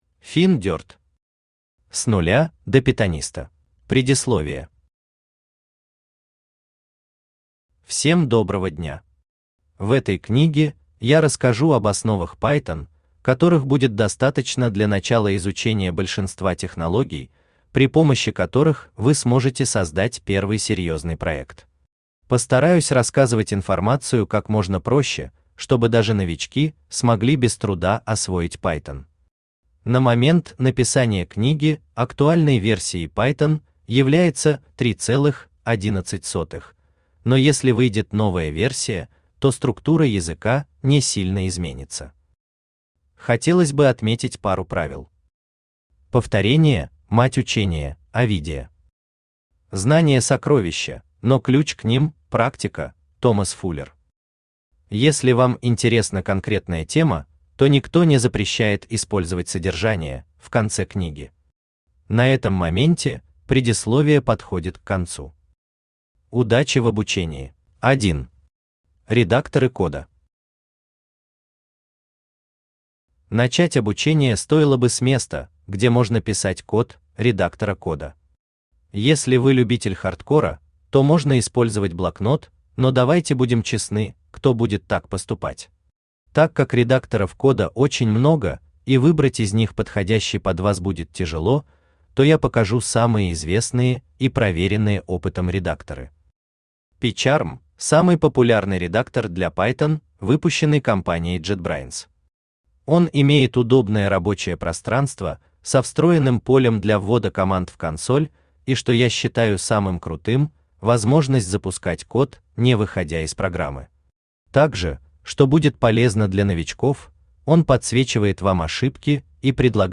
Читает: Авточтец ЛитРес
Аудиокнига «С нуля до питониста».